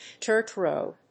アクセントdírt róad